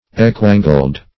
Equiangled \E"qui*an`gled\